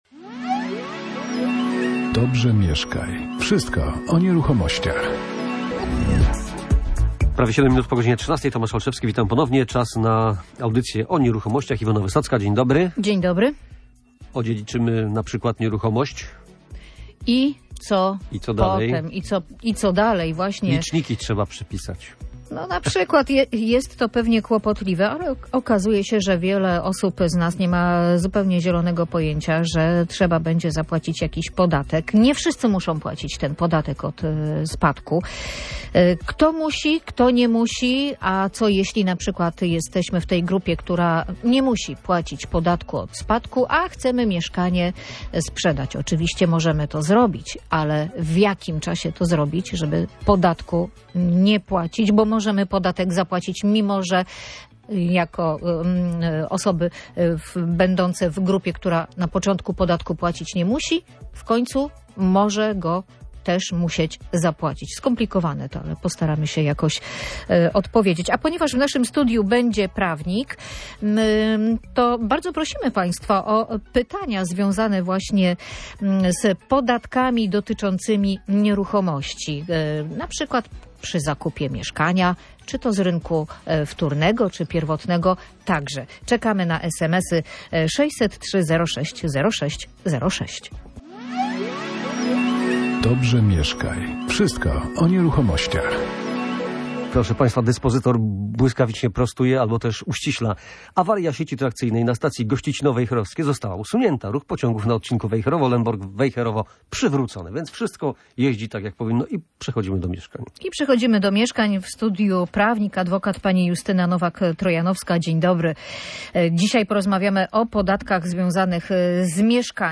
Na te pytania odpowiadała ekspertka.